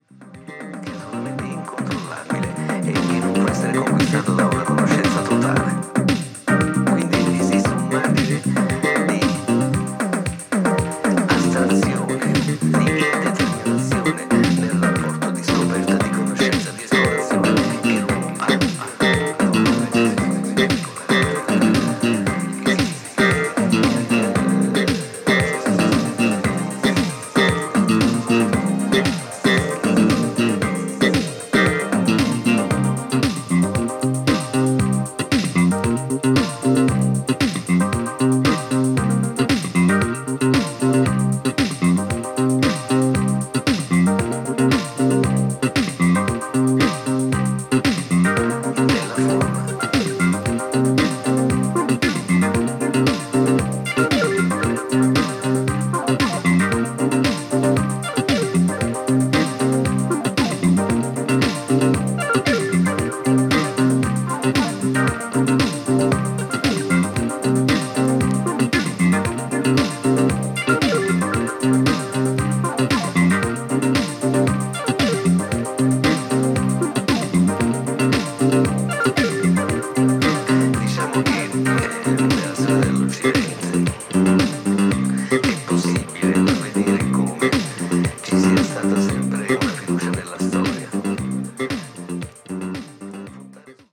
(試聴音源録りました)